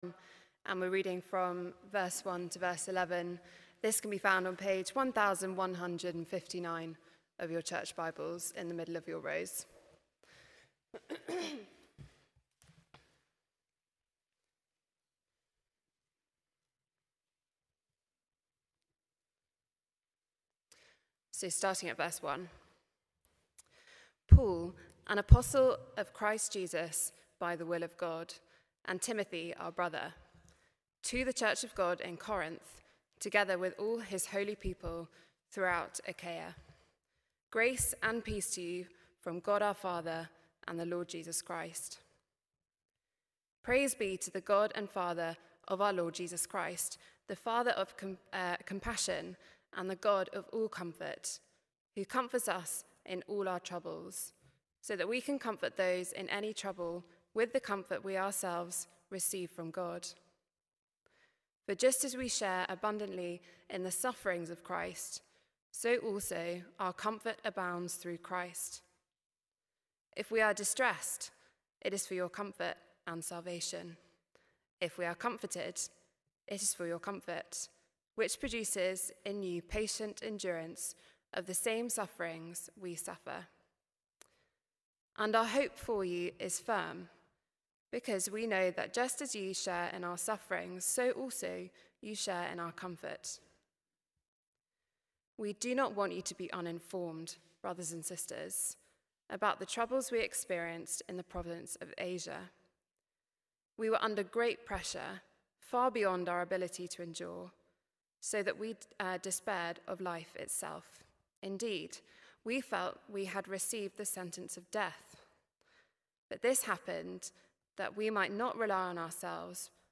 Talks
2 Corinthians 1:8-11 – 18th January 2026 – PM Service